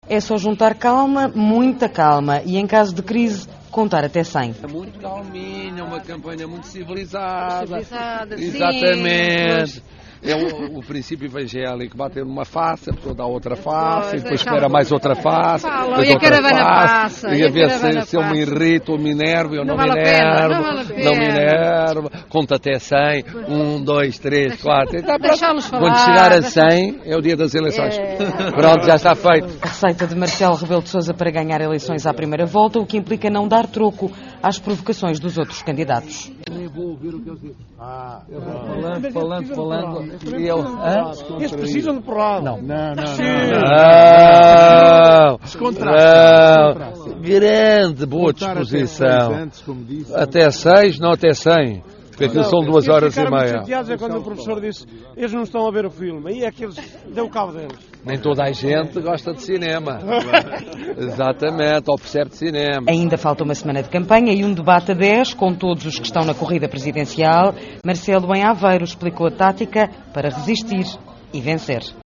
Em Aveiro, este domingo, Marcelo Rebelo de Sousa explicou a táctica para resistir e vencer.